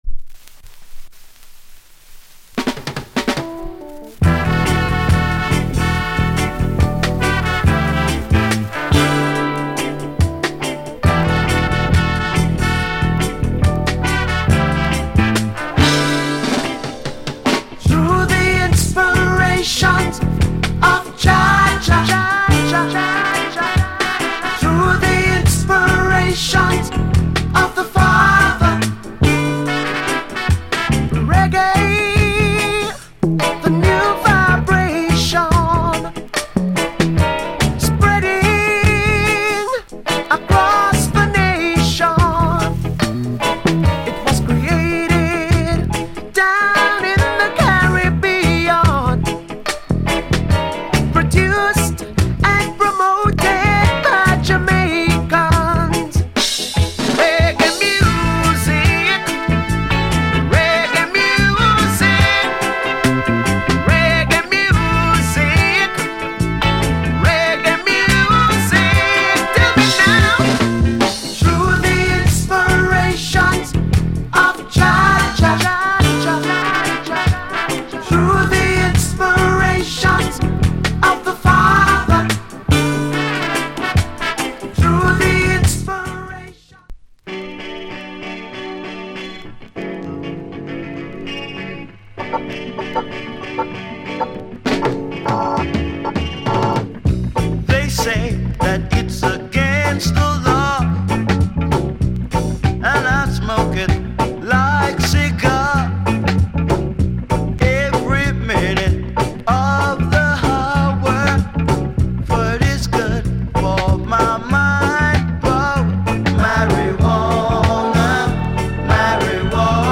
Genre Roots Rock / Male Vocal Group Vocal
** A-1イントロ部分にスプレーノイズ入ります。